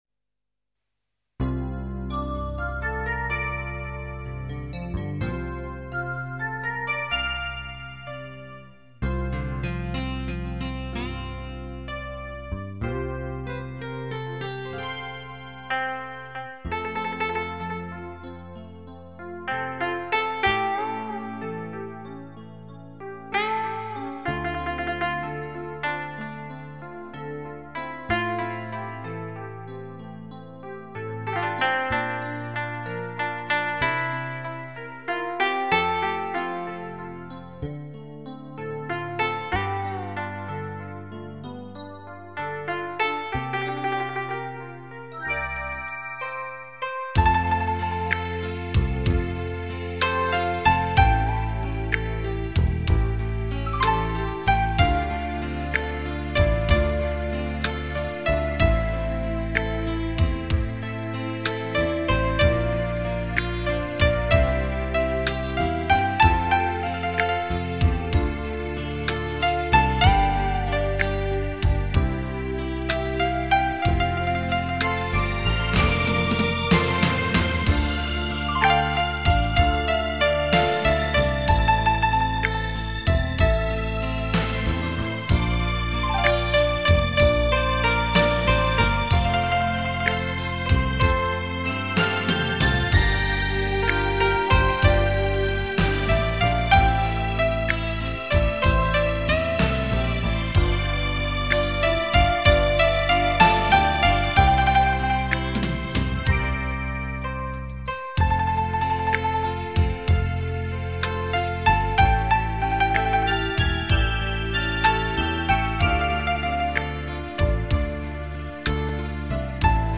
民族加电声乐器专辑
用民族乐器二胡 笛子 古筝在电子乐器的伴奏下演绎港台流行金曲，让我们在舒缓 动听 熟悉的旋律下休闲放松，健身体魄。